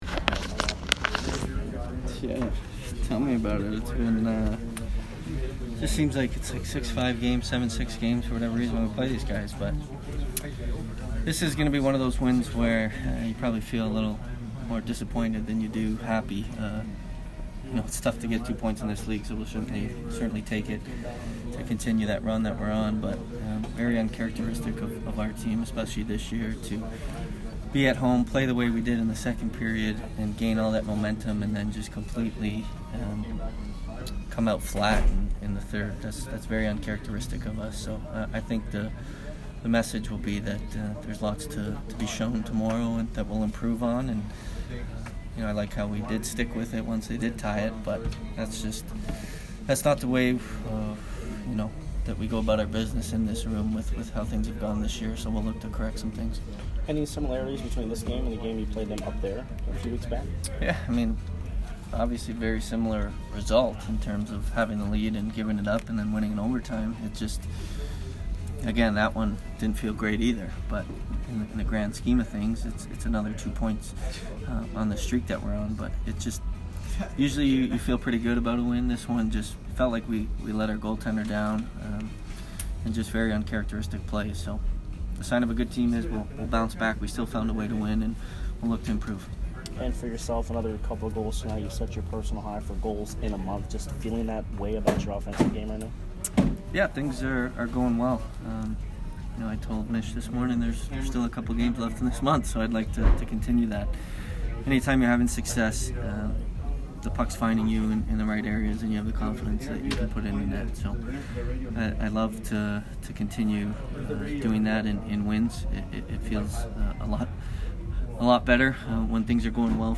Steven Stamkos post-game 12/27